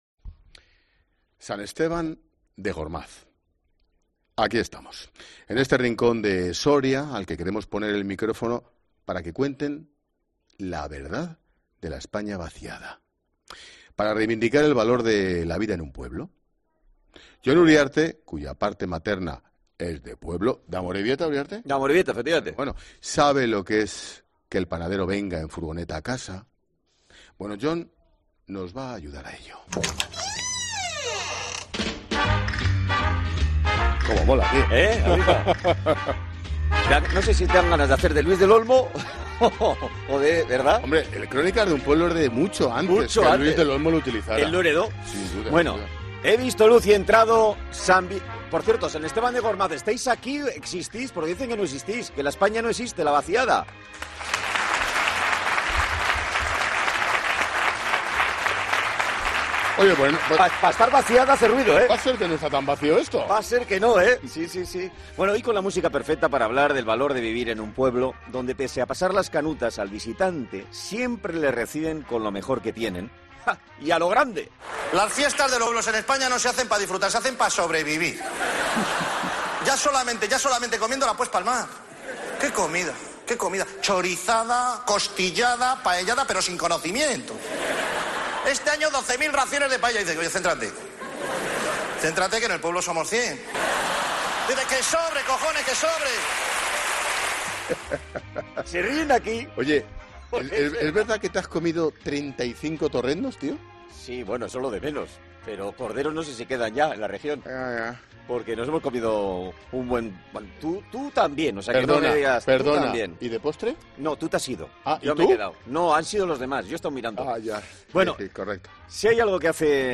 Aquí hemos hecho La Linterna. En este rincón de Soria al que queremos poner el micrófono para que cuenten la verdad de la España vacía... y vaciada.